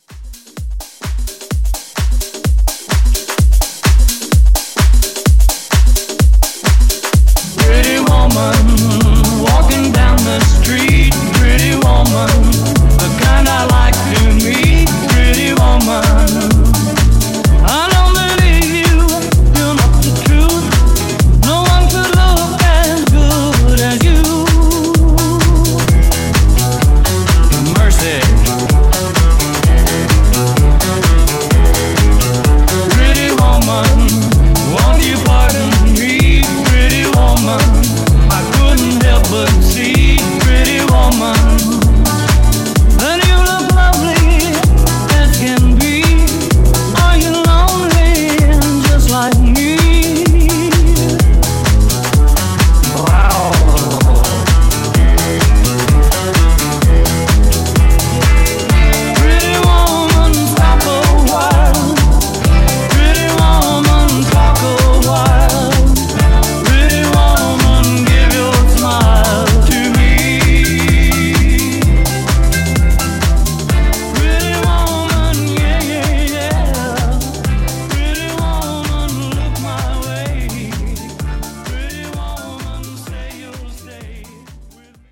Genre: HIPHOP
Dirty BPM: 98 Time